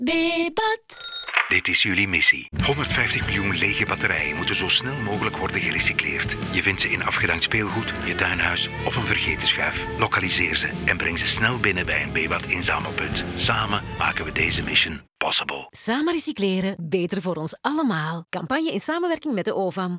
Om Belgen aan te moedigen om de batterijen op te sporen worden ze aangewakkerd om de Hercule Poirot in zichzelf te ontdekken met een campagne in ware detectivestijl.
Bebat - Mission Possible - radio NL.wav